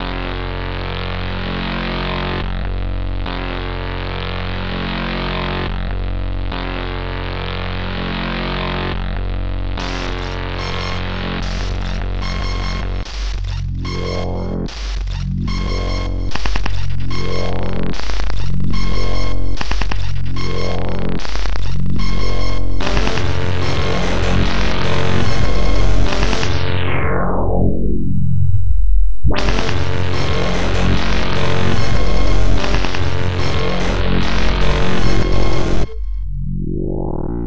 This song only feautres databent sounds.
Oh yeah and effects on the sounds to not break your ears.